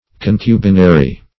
Search Result for " concubinary" : The Collaborative International Dictionary of English v.0.48: Concubinary \Con*cu"bi*na*ry\, a. [LL. concubinarius.] Relating to concubinage; living in concubinage.